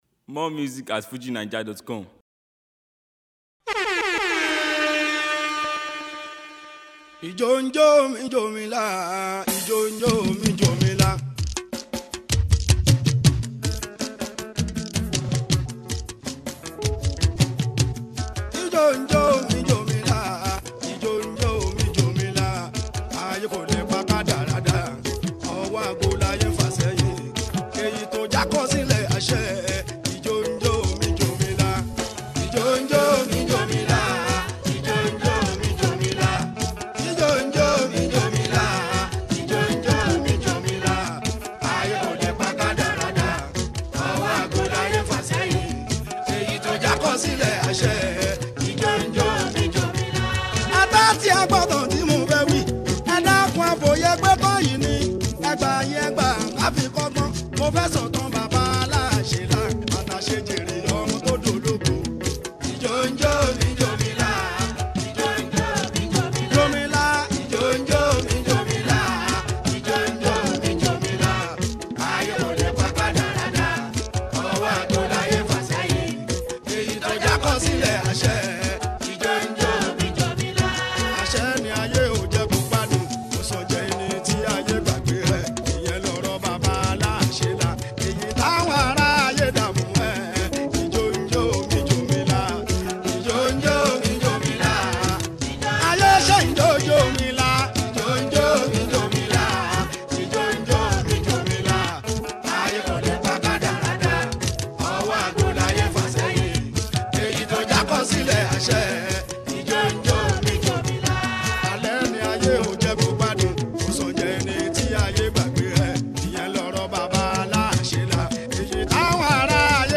body grooving mixtape
Fuji Mixtape